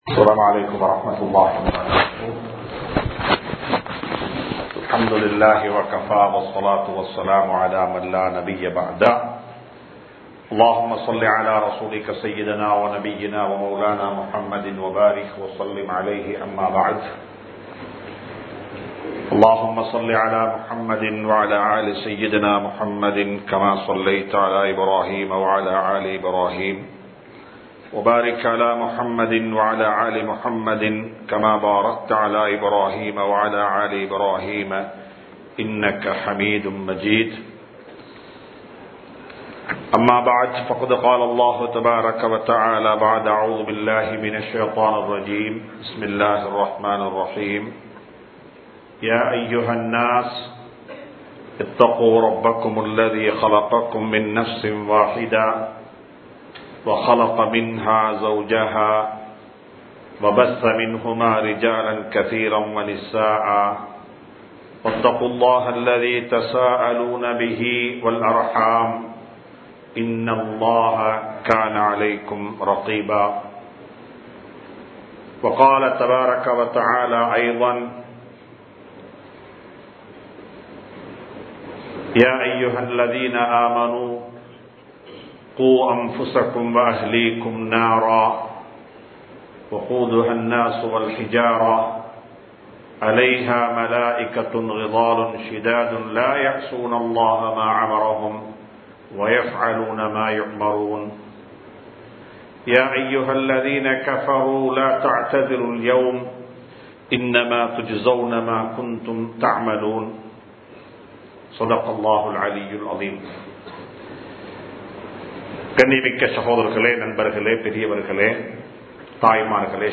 திருமணமும் குடும்ப வாழ்க்கையும் (Day 01) | Audio Bayans | All Ceylon Muslim Youth Community | Addalaichenai
Colombo 06, Mayura Place, Muhiyadeen Jumua Masjith